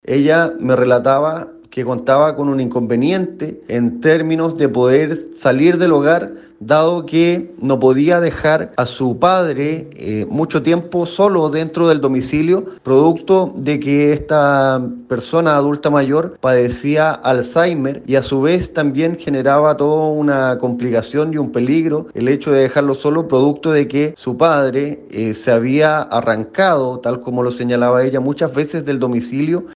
Así lo explicó el alcalde de esa comuna, Sebastián Cruzat.